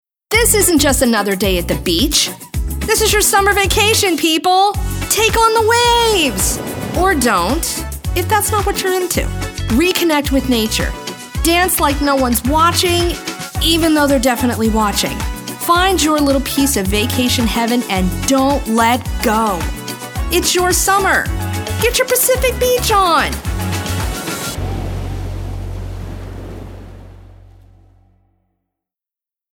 announcer, confident, conversational, cool, friendly, mature, perky, professional, promo